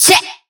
VR_vox_hit_check2.wav